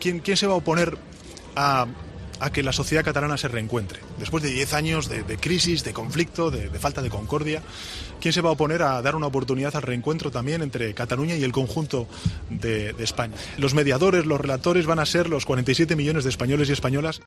Palabras de Pedro Sánchez